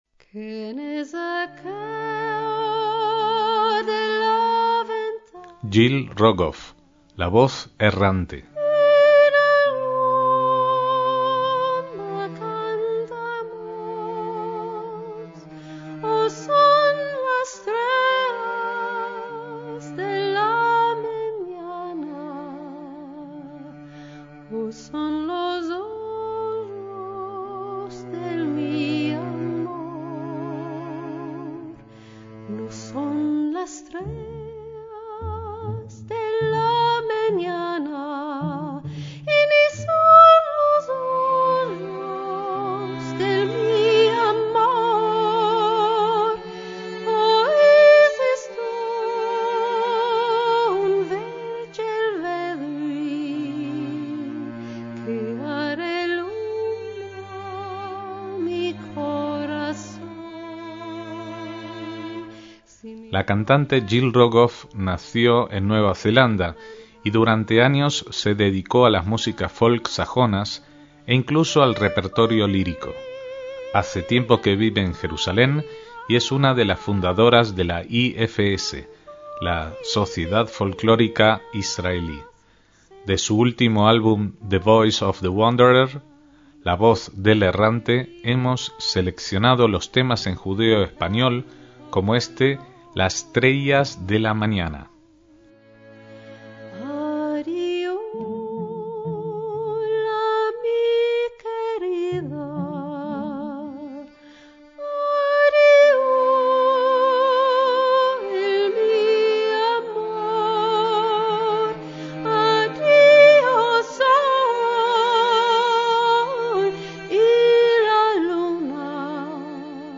MÚSICA SEFARDÍ